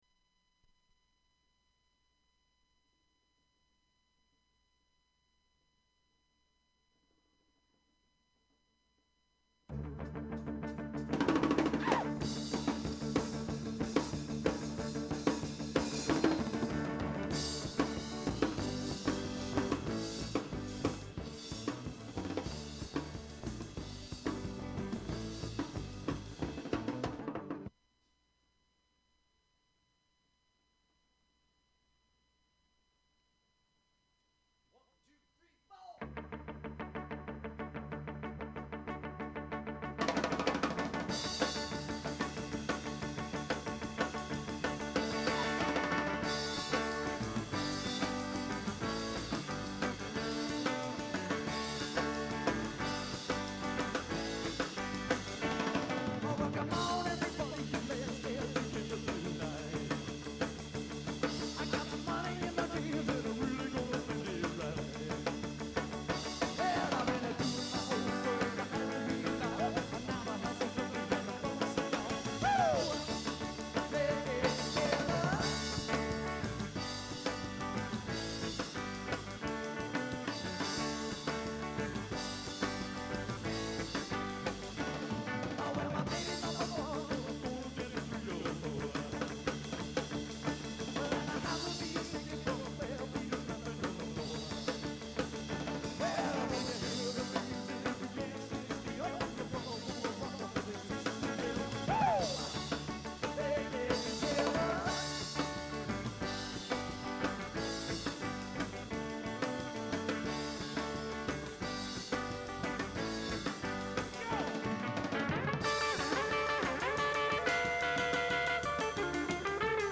Their performances include covers of popular songs
band chatting amongst themselves and long silence
Open reel audiotape